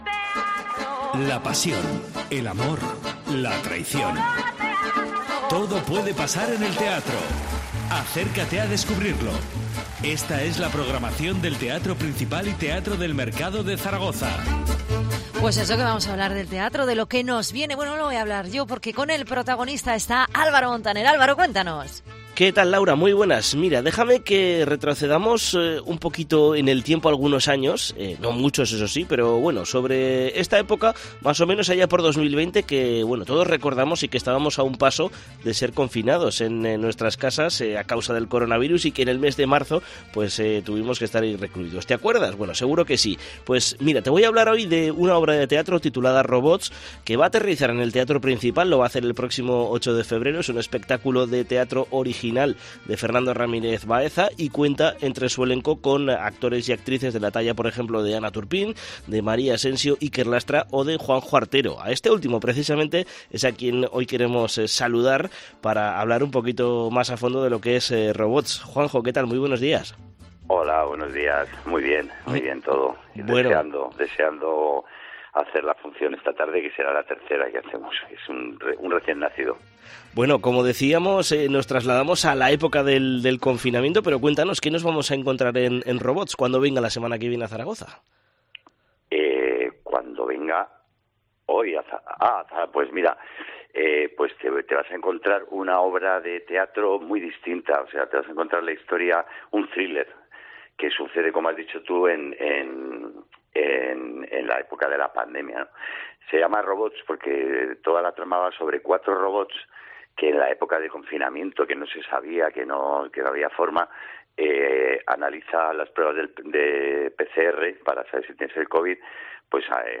Entrevista a Juanjo Artero, que llega al Teatro Principal la semana que viene con 'Robots'
ARTERO, EN COPE ZARAGOZA